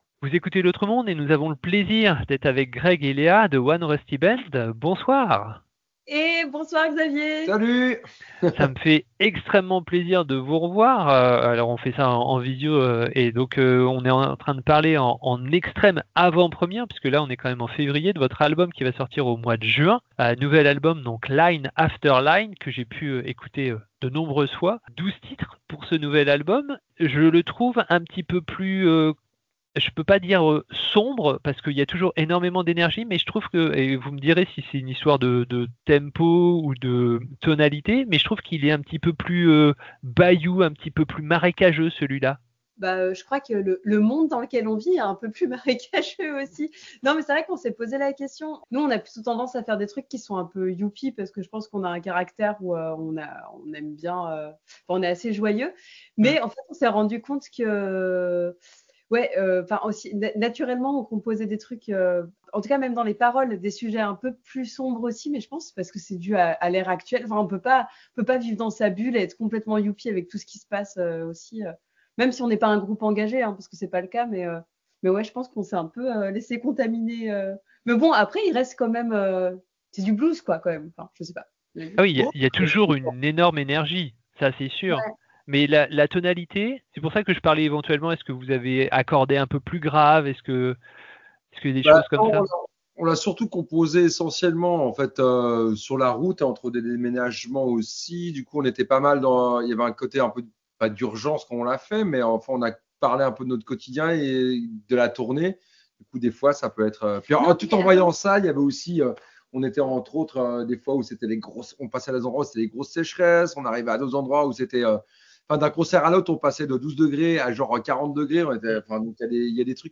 Durée de l'interview : 47 minutes